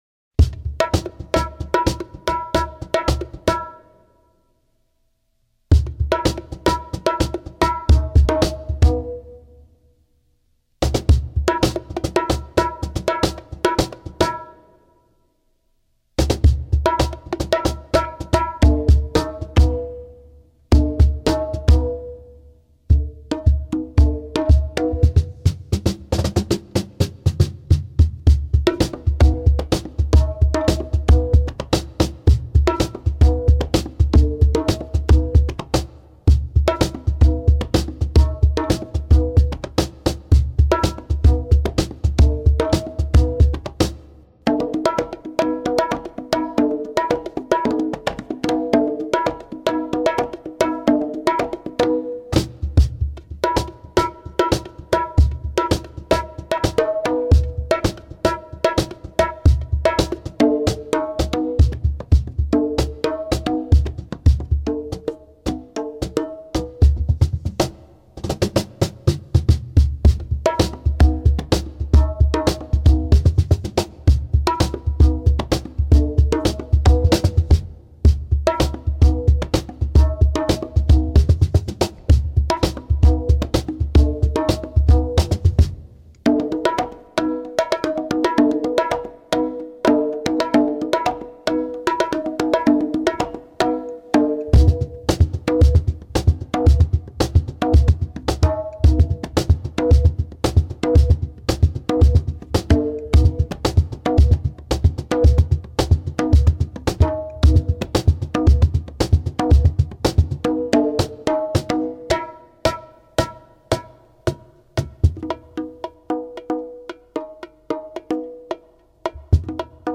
Voicing: Hand Percussion Unaccompanied